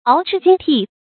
鰲擲鯨呿 注音： ㄠˊ ㄓㄧˋ ㄐㄧㄥ ㄑㄩˋ 讀音讀法： 意思解釋： 見「鰲擲鯨吞」。